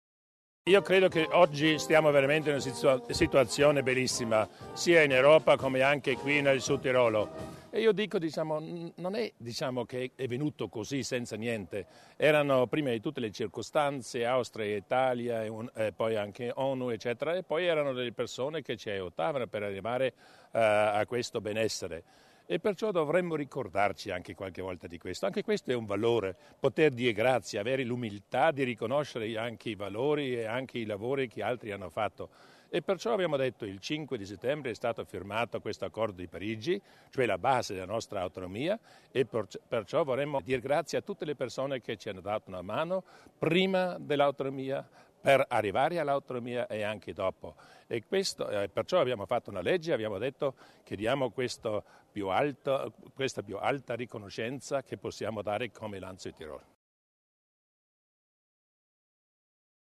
Il Presidente Durnwalder sull'importanza dell'evento